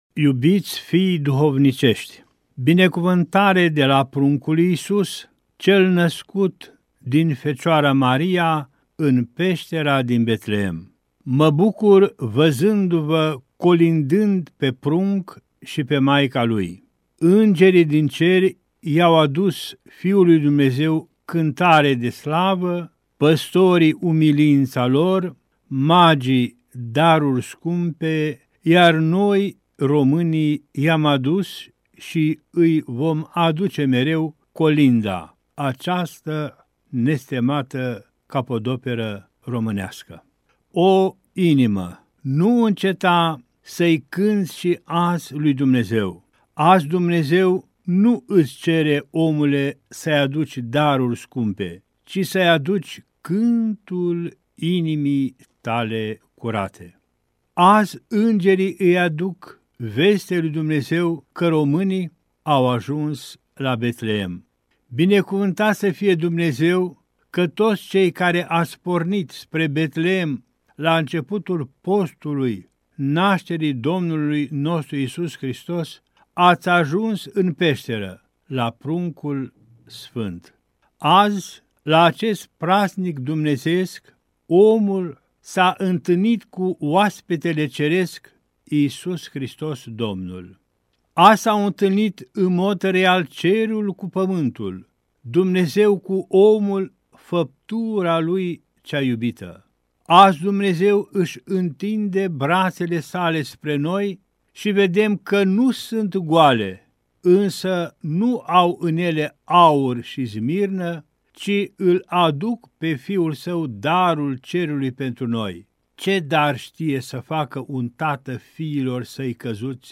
Înalpreasfinția Sa, Ioan, Mitropolitul Banatului transmite un mesaj cu prilejul sărbătorii Nașterii Domnului.